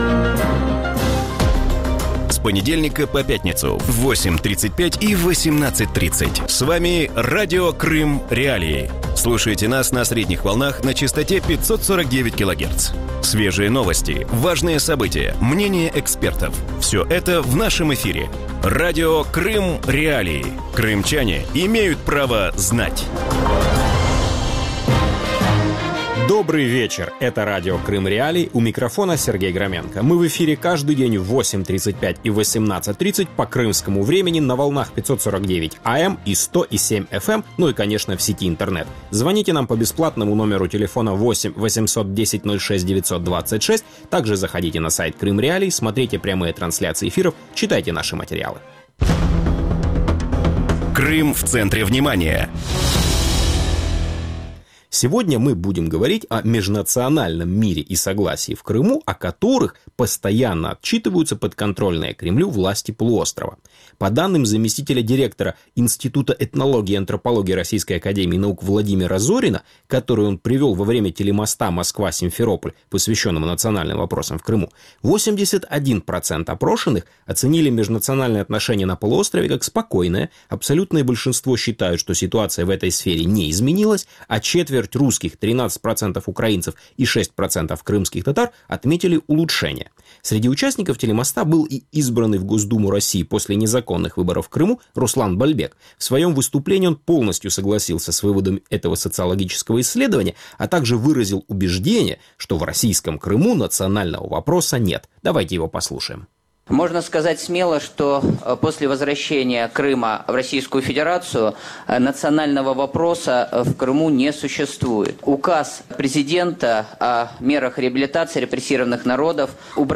У вечірньому ефірі Радіо Крим.Реалії обговорюють міжнаціональні відносини в Криму.